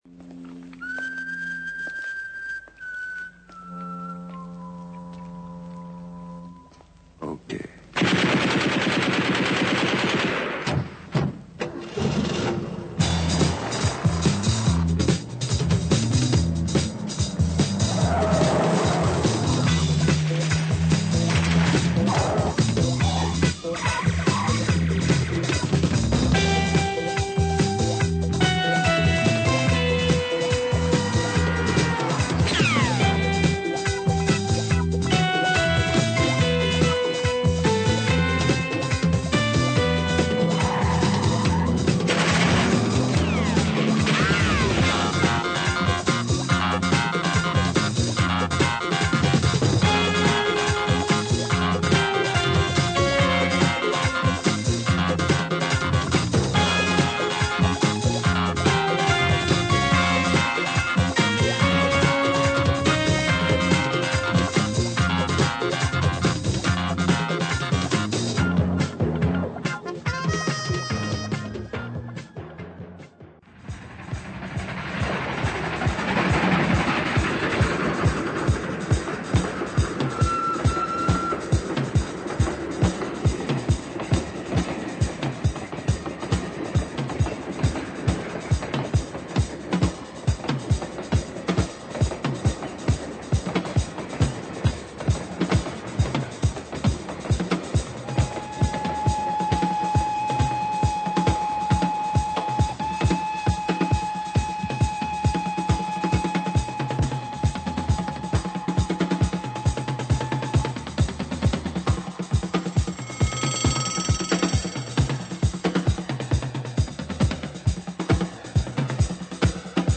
Excellent funky tracks, pure blaxploitation chase sound !
Funk